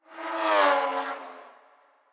pbs - vettepassby [ Sfx ].wav